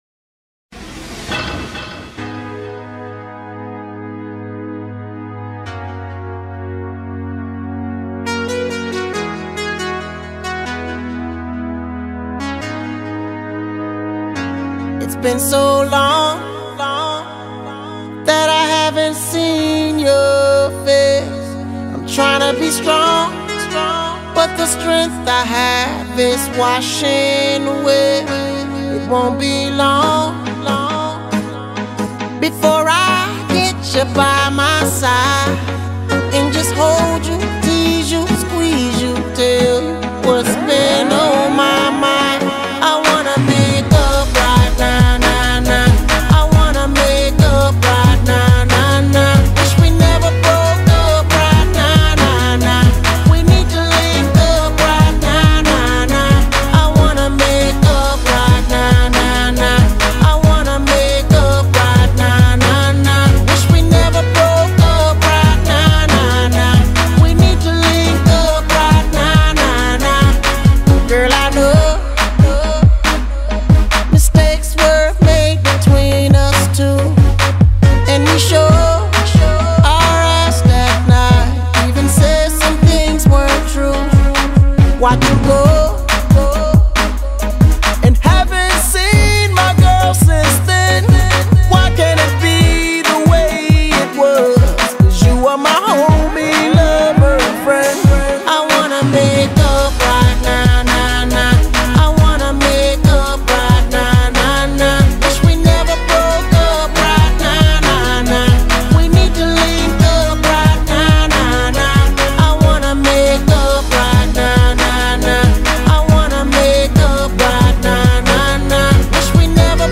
Posted inMusic Throwback music